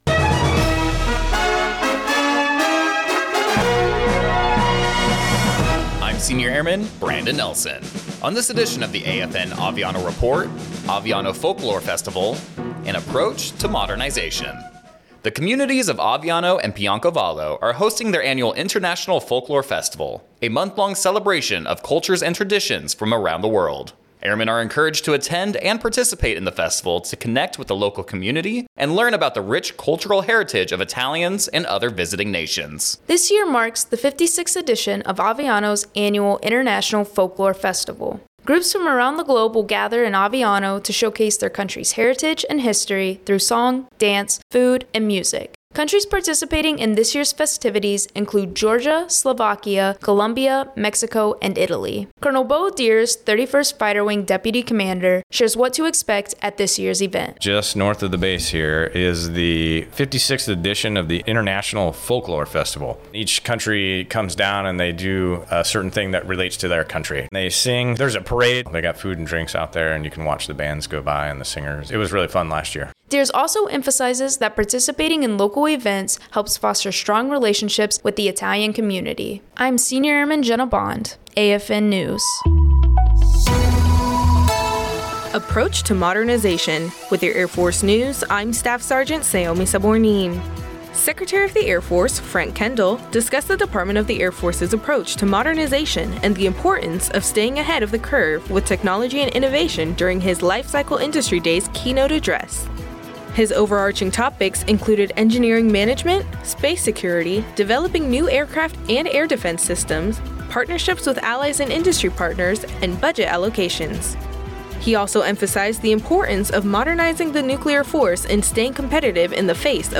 American Forces Network (AFN) Aviano radio news reports on Aviano’s International Folklore Festival celebrating the cultures of numerous countries from around the world. Airmen are encouraged to attend and participate in the festival to connect with the local community and learn about the rich cultural heritage of Italians and other visiting nations.